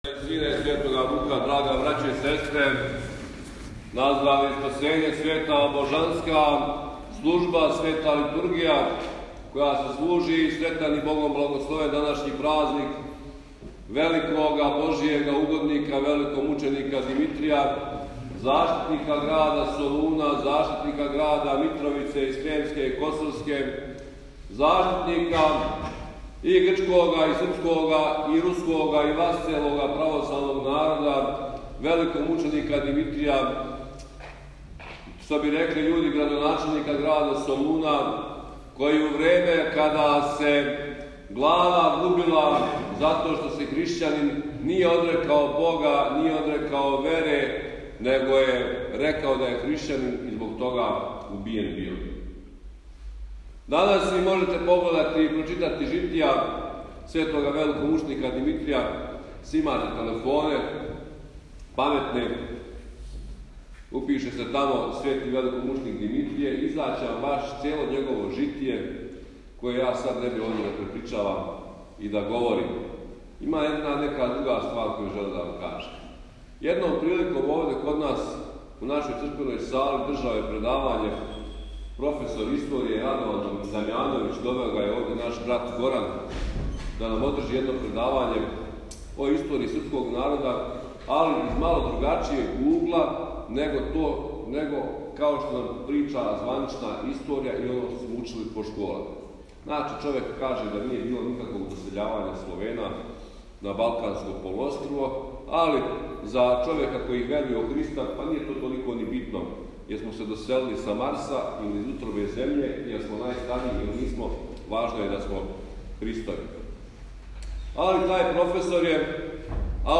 Празновање Митровдана у Сурчину